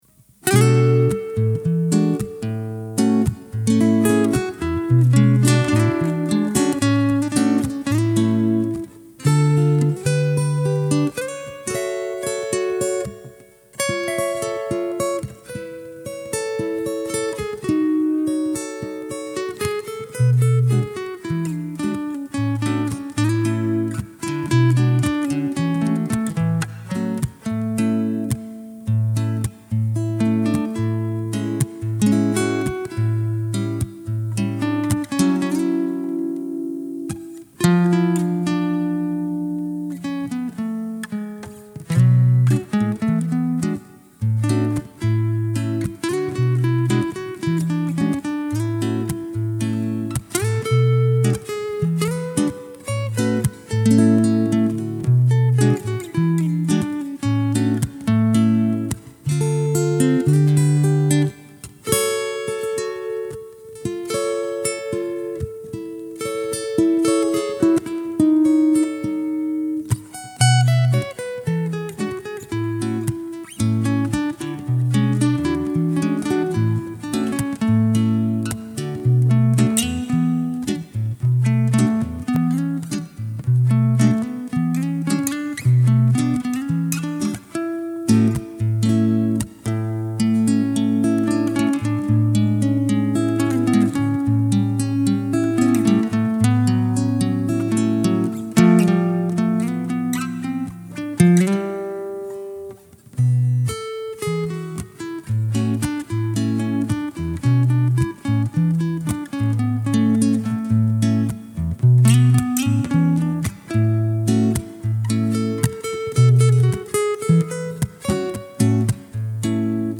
Y de esos días nacen estas canciones: sencillas y sin demasiadas complicaciones.